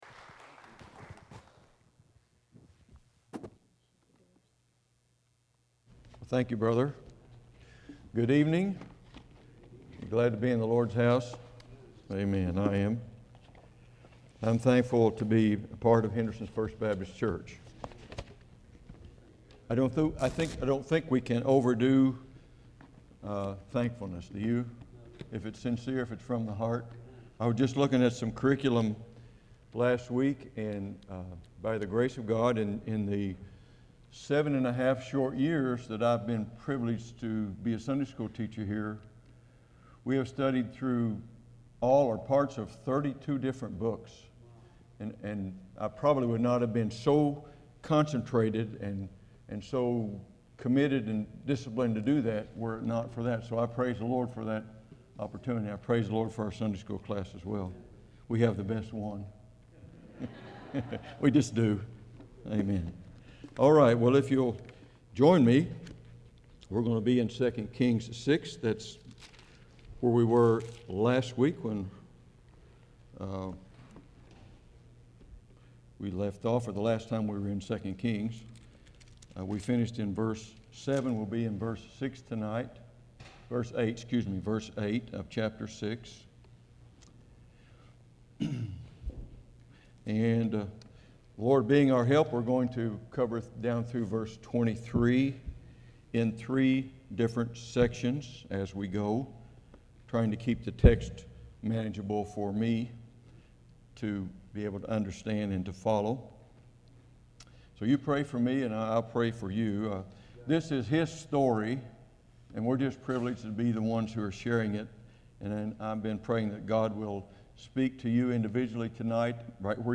2016 The Good The Bad The Ugly 2 Kings This is an evening sermon with no manuscript attached.